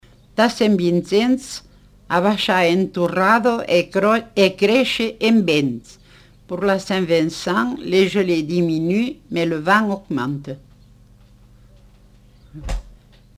Lieu : Montauban-de-Luchon
Type de voix : voix de femme
Production du son : récité
Classification : proverbe-dicton